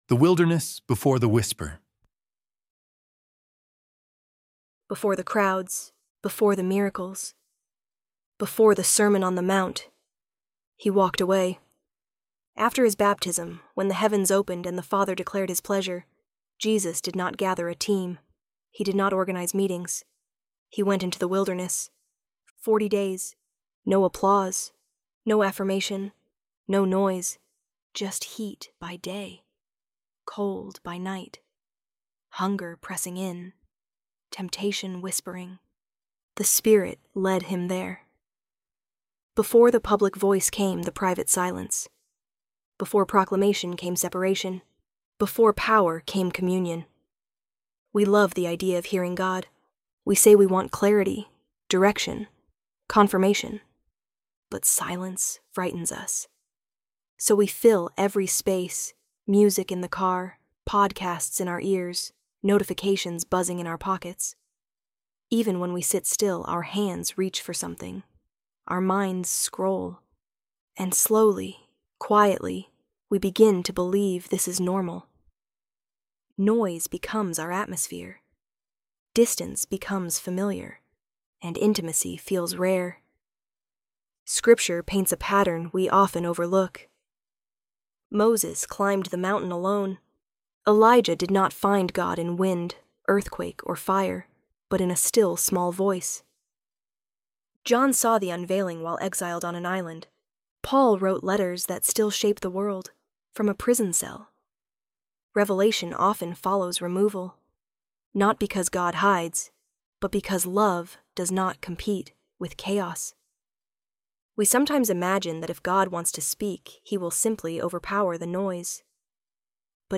ElevenLabs_The_Wilderness_Before_the_Whisper.mp3